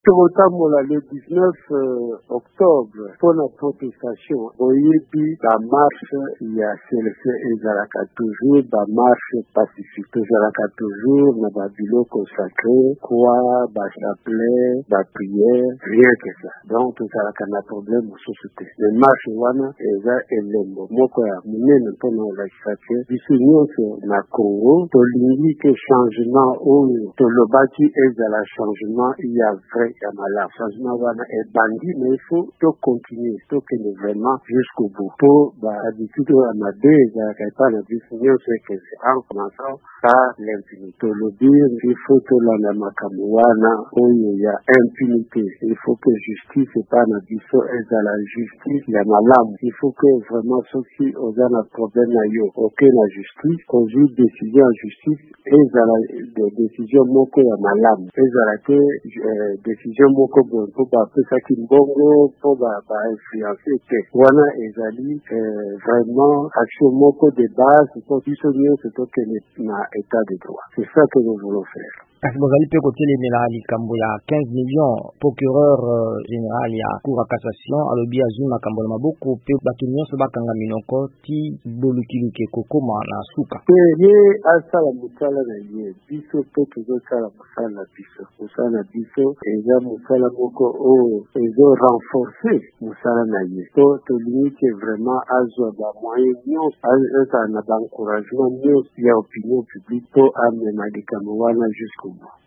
VOA Lingala etunaki moko ya baye ya CLC, professeur Isidore Ndaywel.